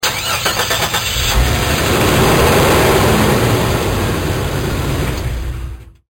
Jeep start